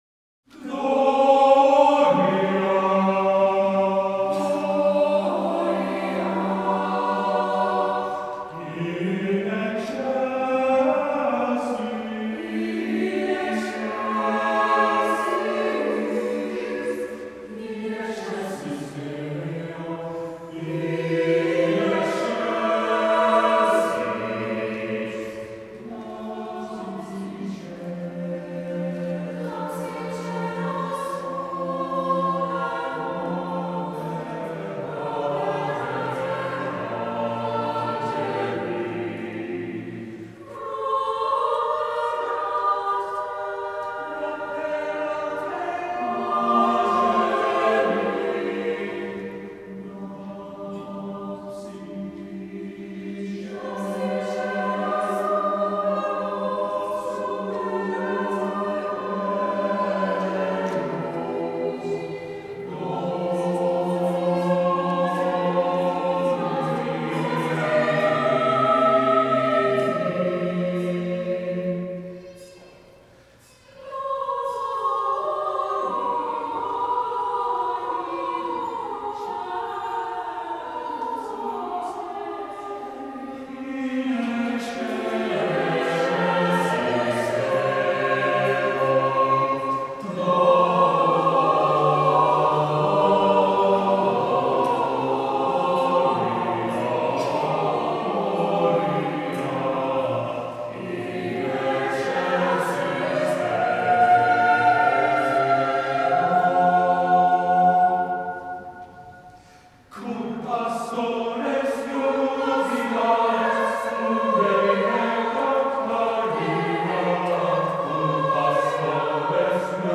HERE ARE SEVERAL reasons I think readers will cherish the following SATB Christmas piece, which is a breathtaking arrangement of Angels We Have Heard on High for unaccompanied SATB choir.
There’s a glorious pedal tone in the bass towards the end. There’s a ‘canonic’ section which is super fun.
Live recording for 2026 by a volunteer choir.